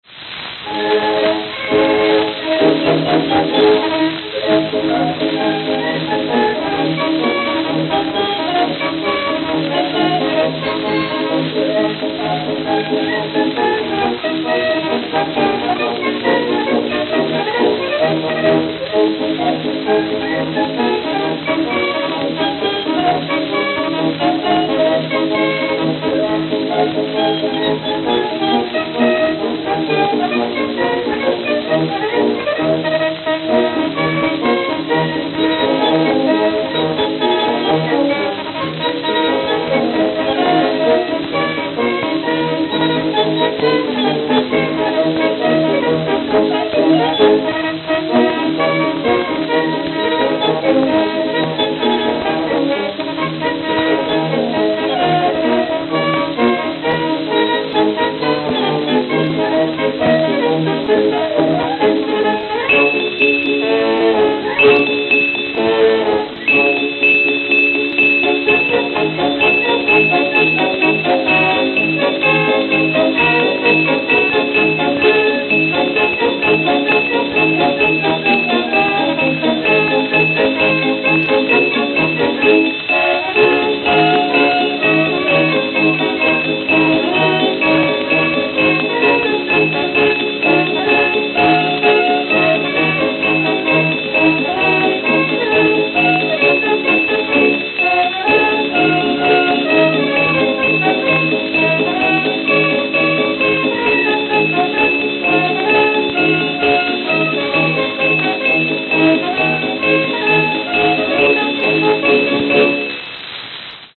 Zon-O-Phone Orchestra
Camden, New Jersey
Note: Grooves closer together towards end.